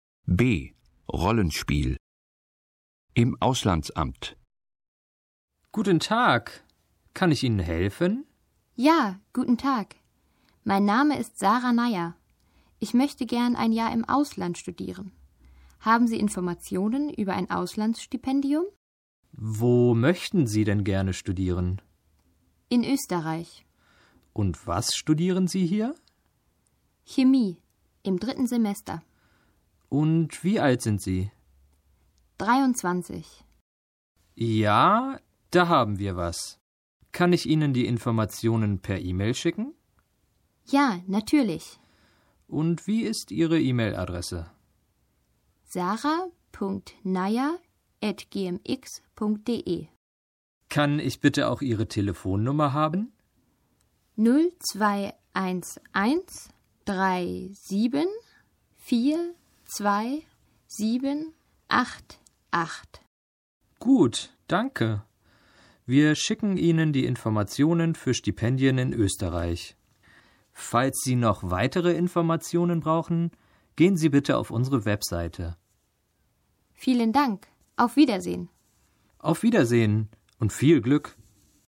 Rollenspiel: Im Auslandsamt (1307.0K)